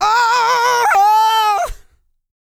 E-GOSPEL 114.wav